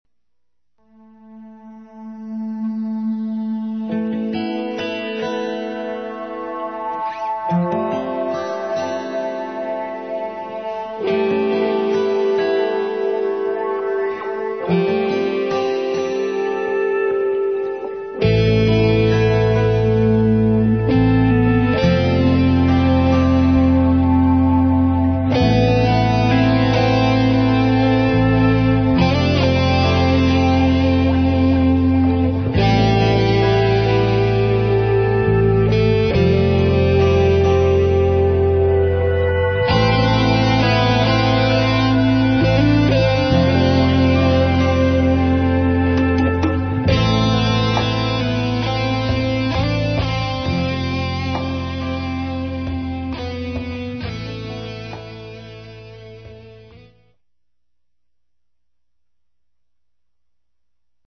Guitar
Vocals / Drums
Bass Guitar
Keyboards
Percussion